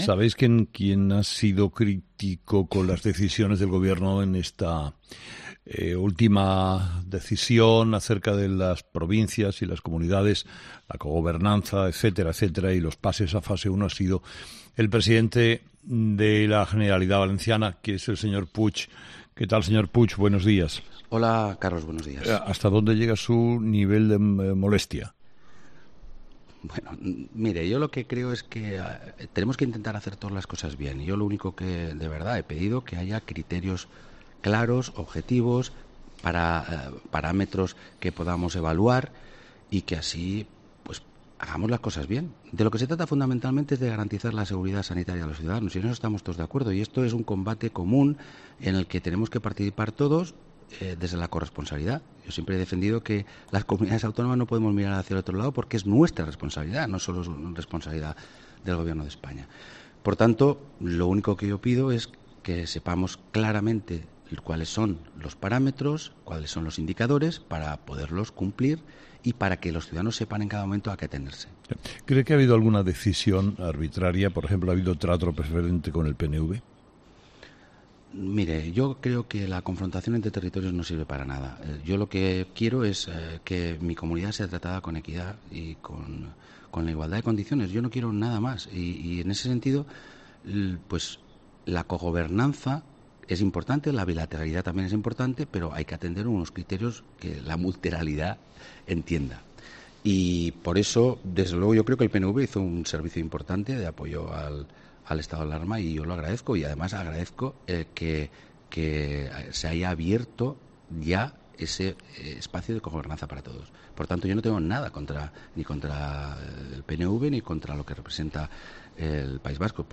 Ximo Puig, presidente de la Generalitat Valenciana, ha sido entrevistado este lunes en 'Herrera en COPE' después de pedir al Gobierno saber los motivos por los que se excluyó de la Fase 1 de la desescalada a su comunidad.